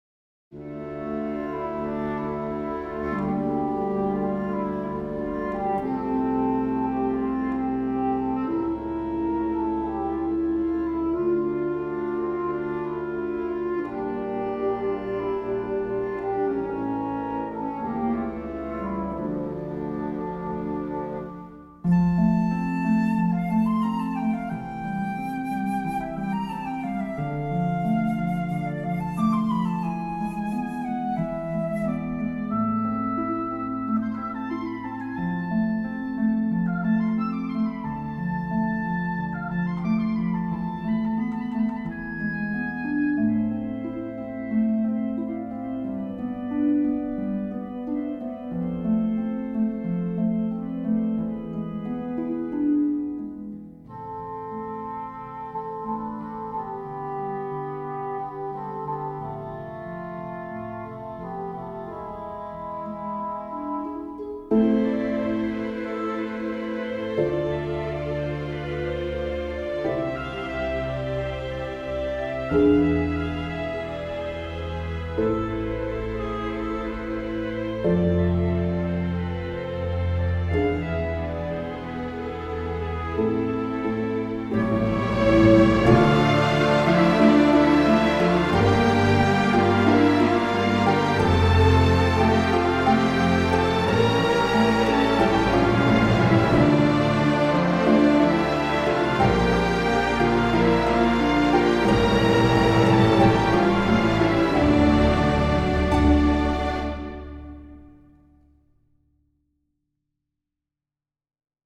Van de stukken voor orkest is er met behulp van StaffPad een synthetische "weergave" worden gemaakt.
Op.15 No.2 LeapFrog Symfonieorkest november 2025 Houtblazers, harp, strijkers, koperblazers en pauken